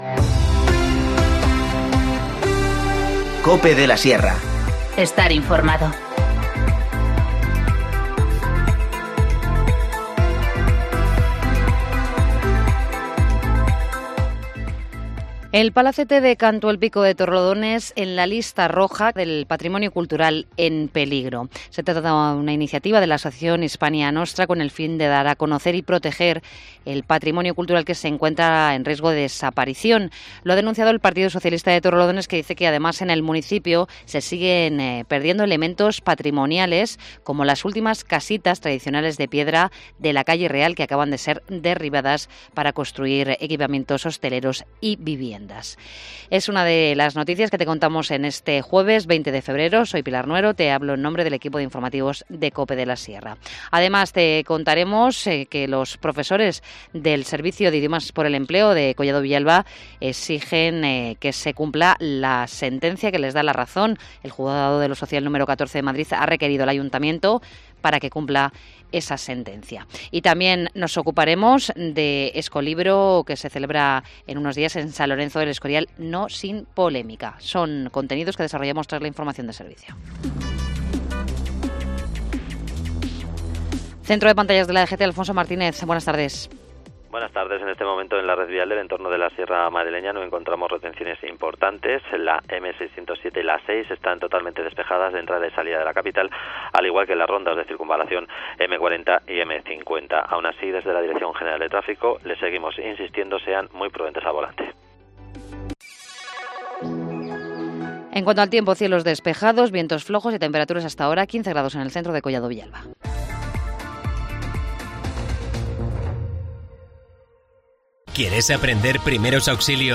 Informativo Mediodía 20 febrero 14:20h